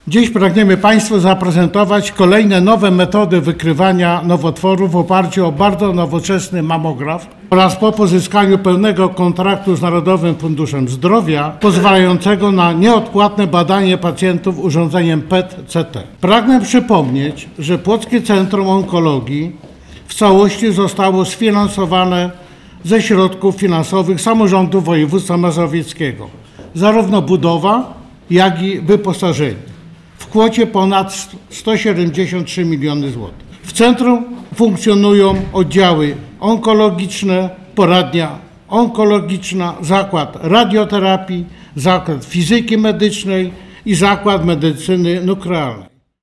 W piątek, 28 listopada, w sali konferencyjnej Wojewódzkiego Szpitala Zespolonego w Płocku odbyła się konferencja pod hasłem „Nowoczesne techniki diagnostyczne w leczeniu nowotworów”.